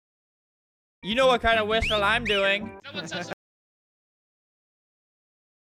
Flamingo Wolf Whistle Sound Button - Free Download & Play
Reactions Soundboard251 views